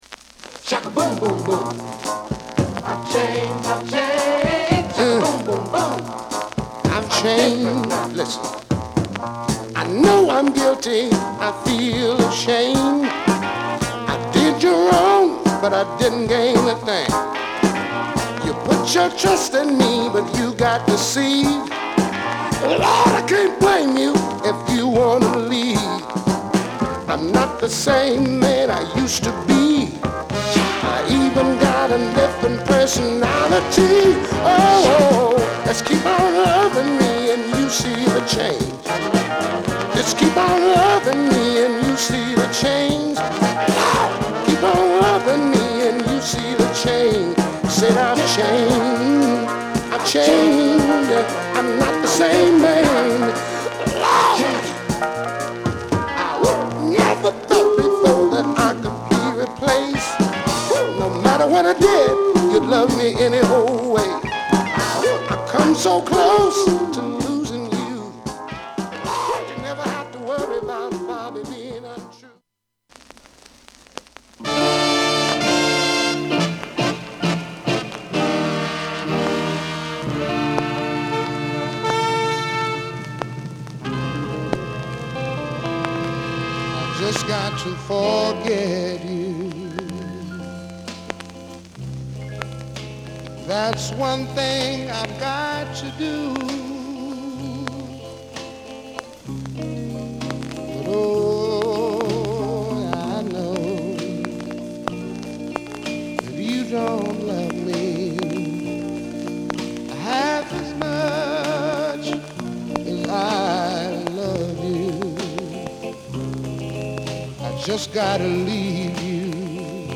R&B / SOUL